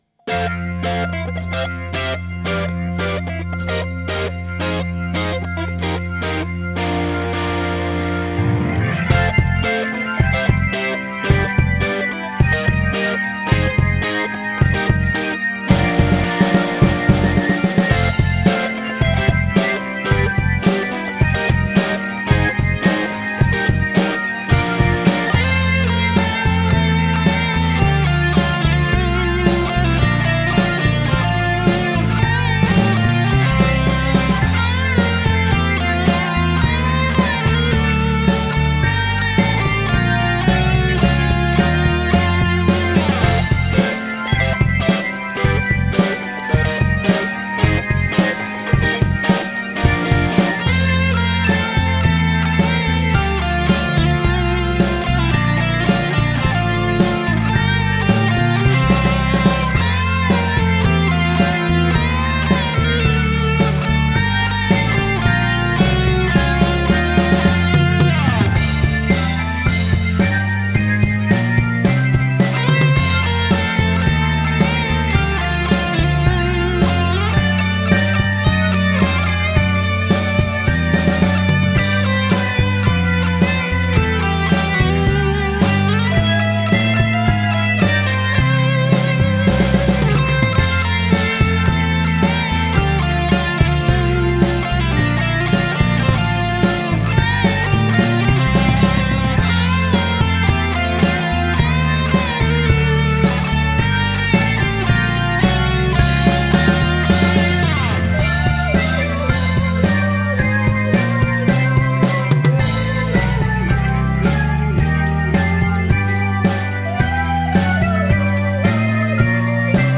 Lead and rhythm guitar, bass and occasional drums/percussion